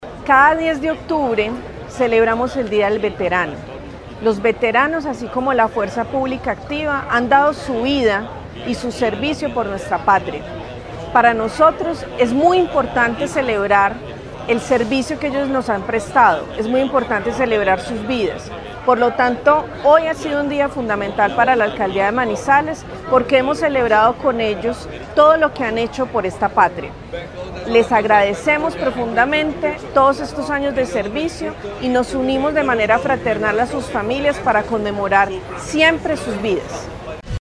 Paula Andrea Sánchez, secretaria del Interior de Manizales.
Paula-Andrea-Sanchez-secretaria-del-Interior-de-Manizales.mp3